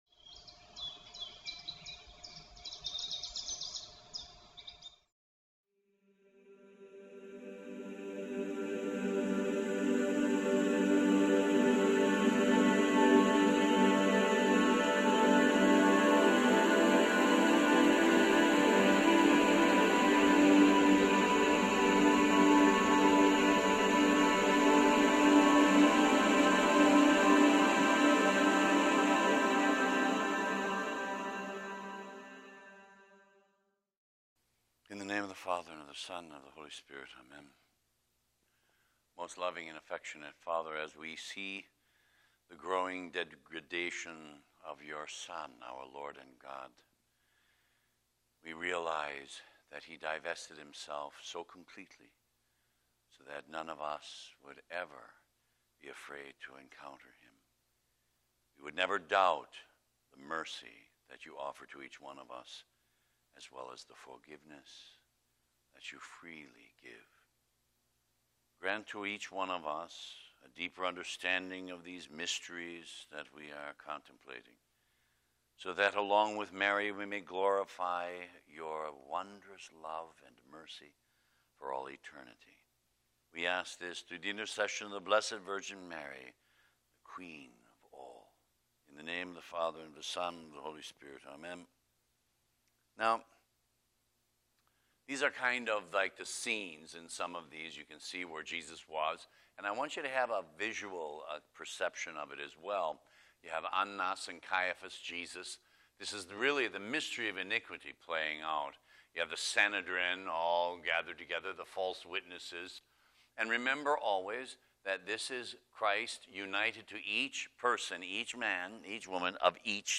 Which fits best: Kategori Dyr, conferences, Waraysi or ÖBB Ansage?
conferences